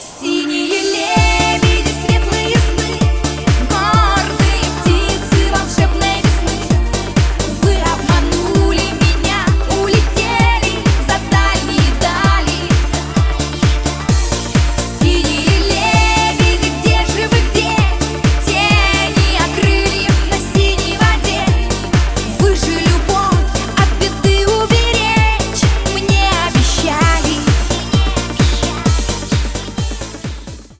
• Качество: 320, Stereo
поп
громкие
женский вокал